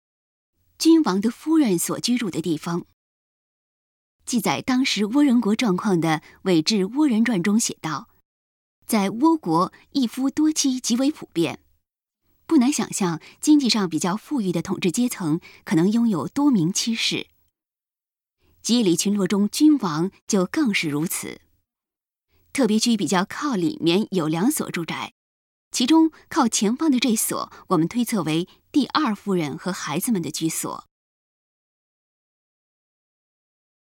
特别区比较靠里面有两所住宅，其中，靠前方的这所，我们推测为第二夫人和孩子们的居所。 语音导览 前一页 下一页 返回手机导游首页 (C)YOSHINOGARI HISTORICAL PARK